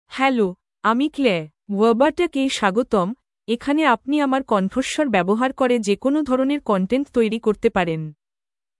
FemaleBengali (India)
ClaireFemale Bengali AI voice
Claire is a female AI voice for Bengali (India).
Voice sample
Listen to Claire's female Bengali voice.
Claire delivers clear pronunciation with authentic India Bengali intonation, making your content sound professionally produced.